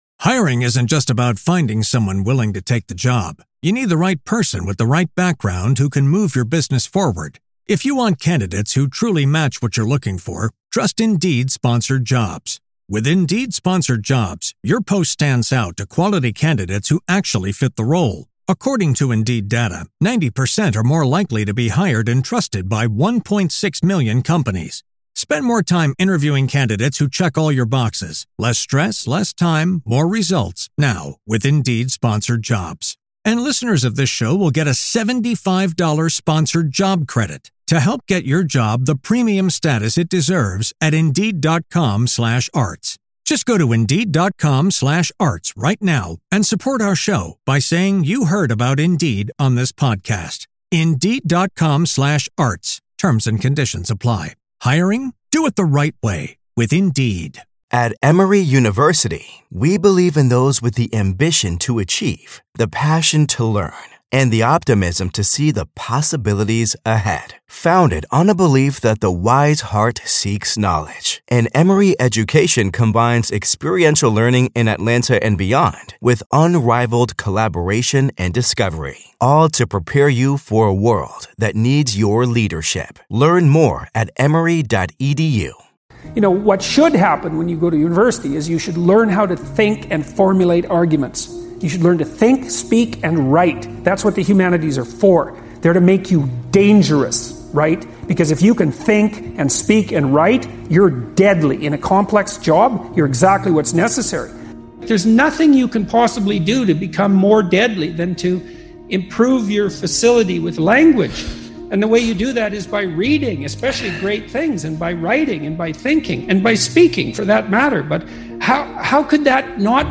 Speaker: Dr. Jordan Peterson